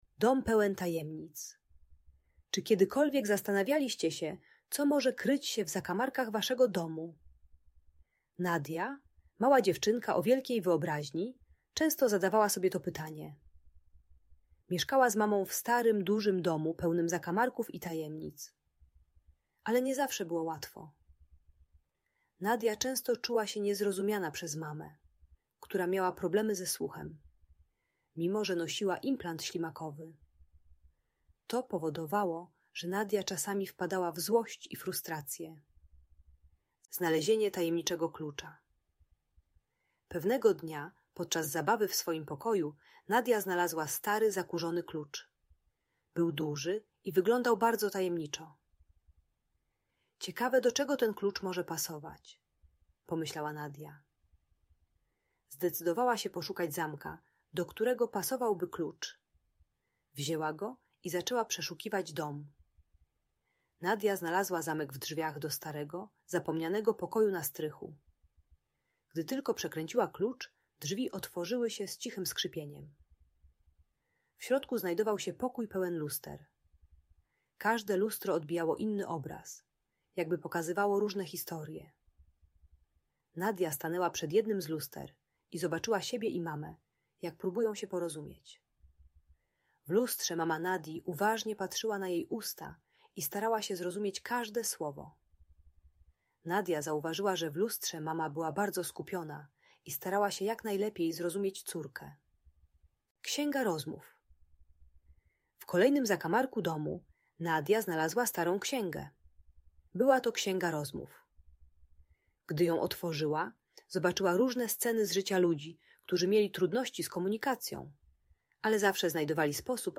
Dom Pełen Tajemnic - Bunt i wybuchy złości | Audiobajka